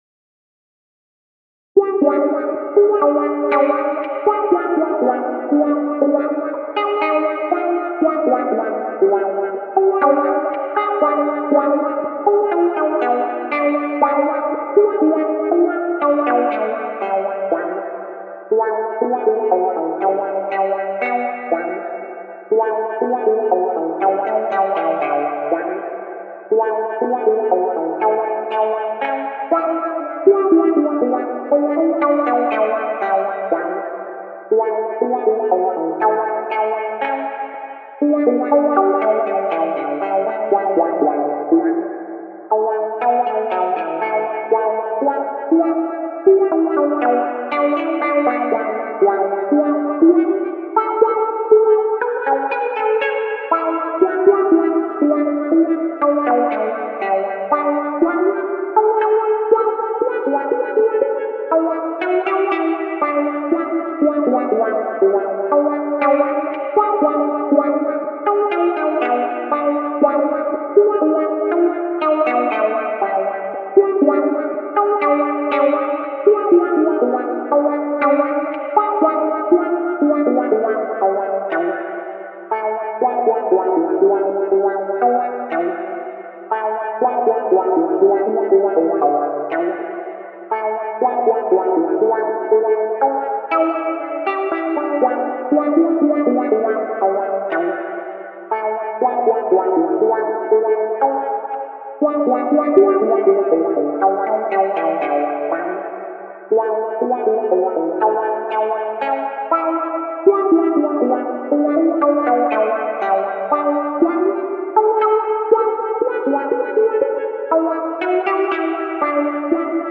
Hier habe ich einige Klangbeispiele zusammengestellt, welche teilweise mit Effekten versehen wurden.
ots-clawhammer-banjo-3wah.mp3